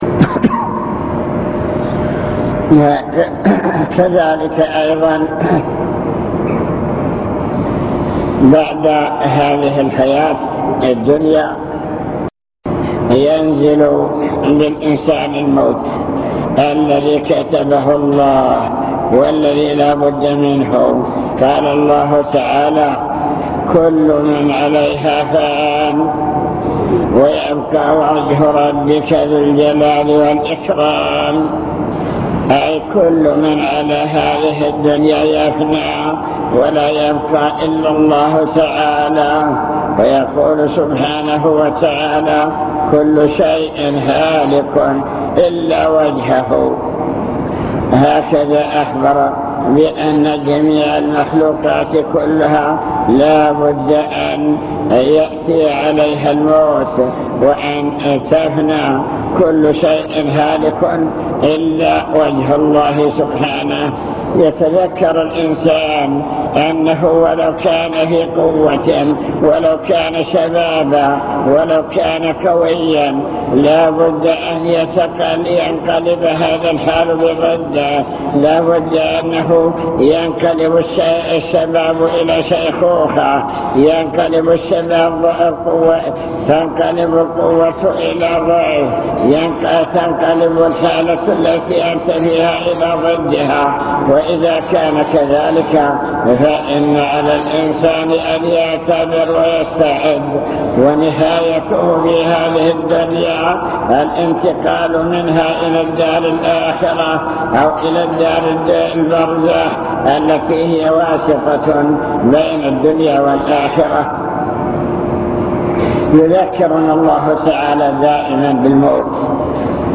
المكتبة الصوتية  تسجيلات - محاضرات ودروس  مواعظ وذكرى